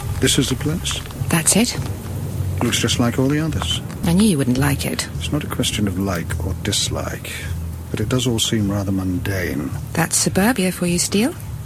Audio drama
Memorable Dialog